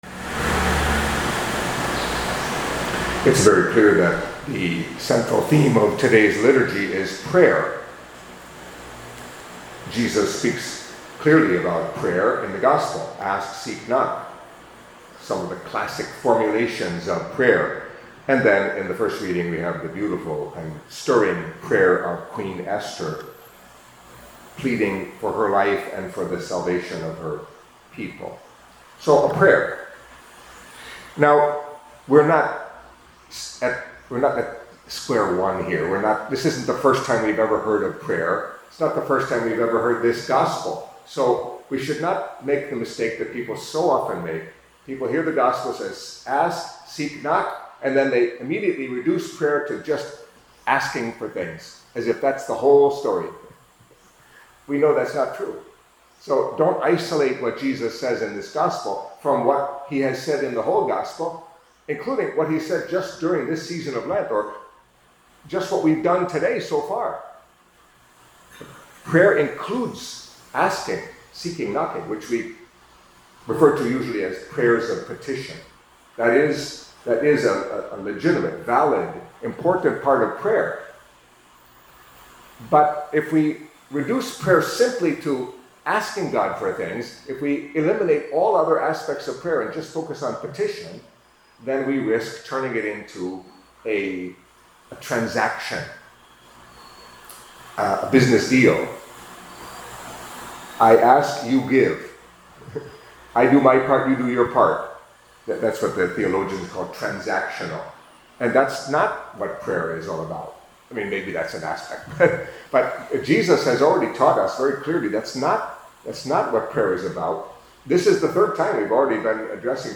Catholic Mass homily for Thursday of the First Week of Lent